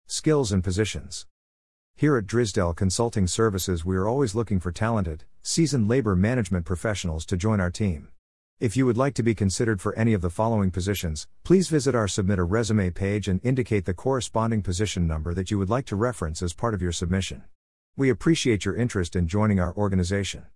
easytts_audio_Skills-and-Positions1-2.mp3